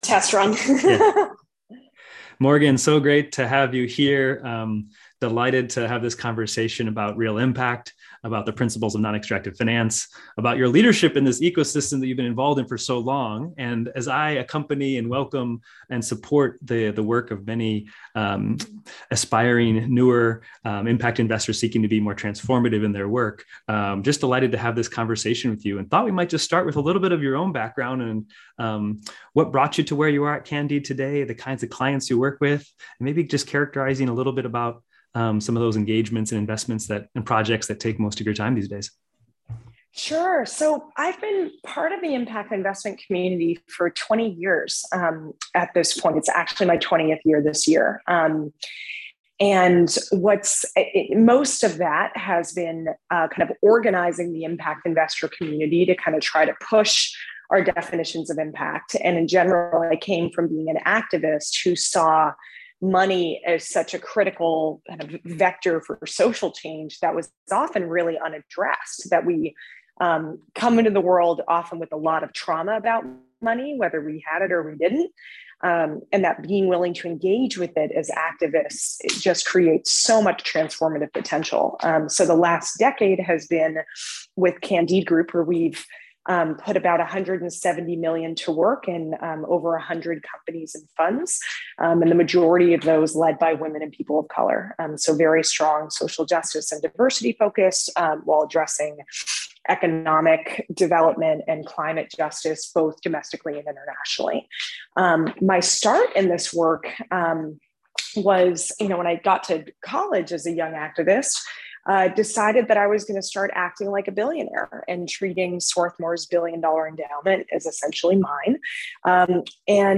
I was grateful to record a conversation with her this afternoon, where she surprised me in how encouraging she was of faith investors.